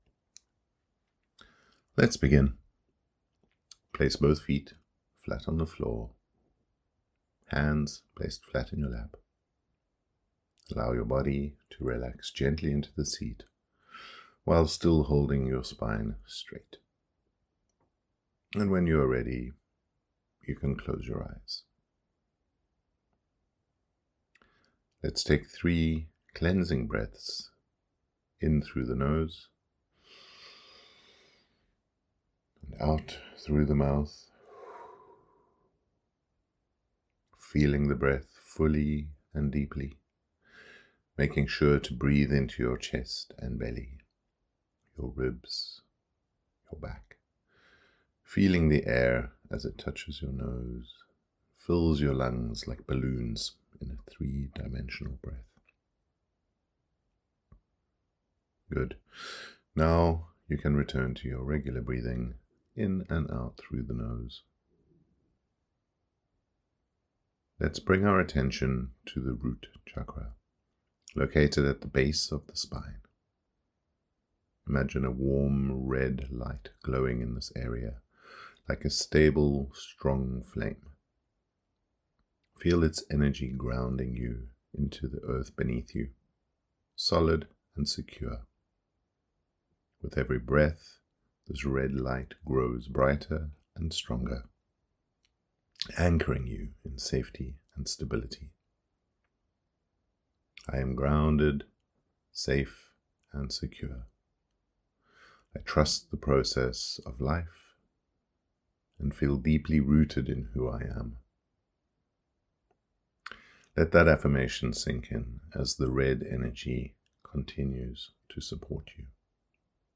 Chakras Points Meditation
CH08-Meditation.mp3